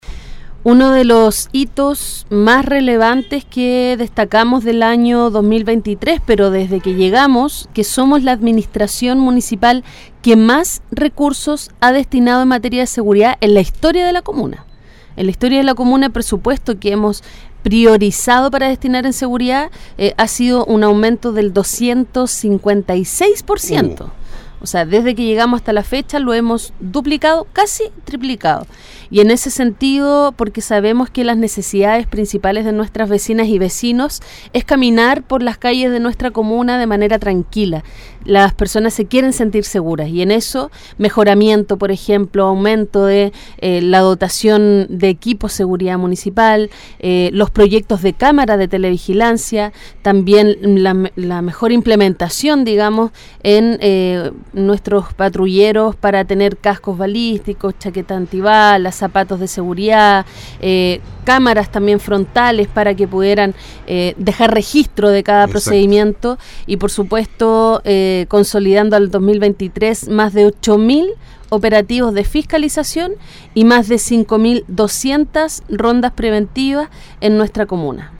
Durante una entrevista realizada en “La Mañana de Todos” de Radio Ignacio Serrano, la alcaldesa Olavarría respondió a las dudas  más importantes para la comuna de Melipilla